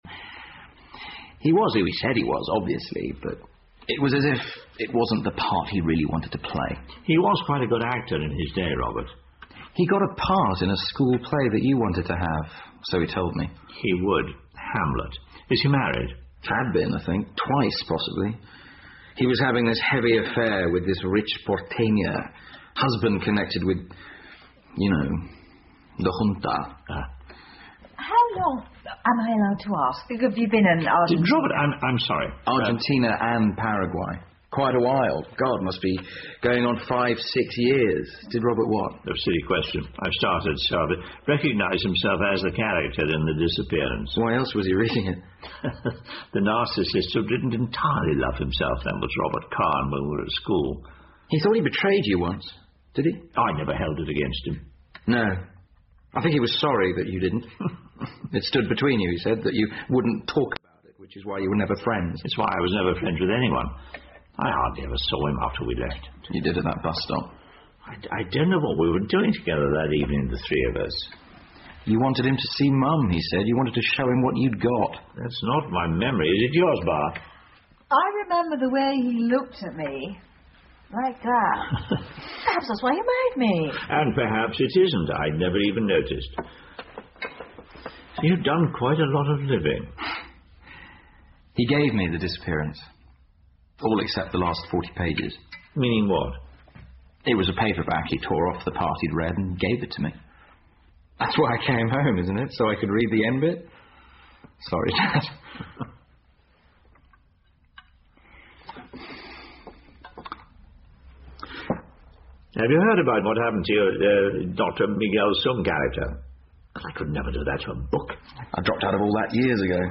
英文广播剧在线听 Fame and Fortune - 42 听力文件下载—在线英语听力室